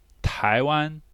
Zh-Taiwan.ogg